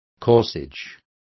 Complete with pronunciation of the translation of corsage.